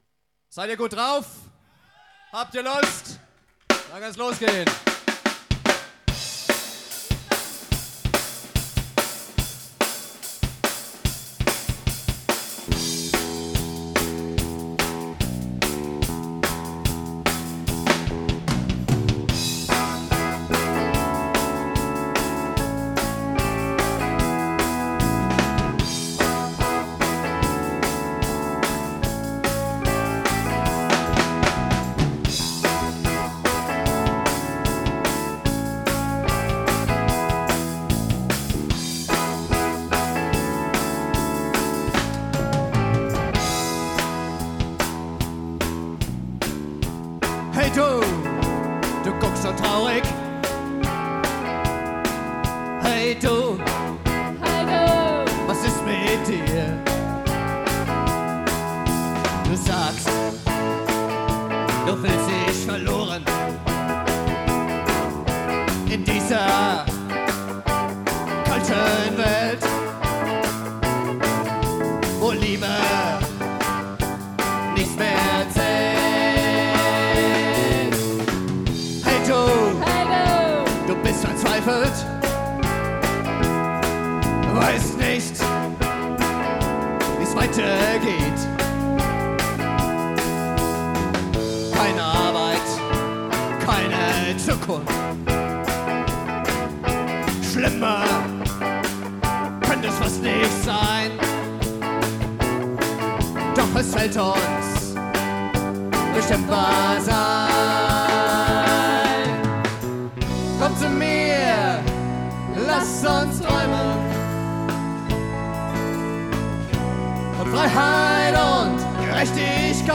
Audio (live; 6:18)Herunterladen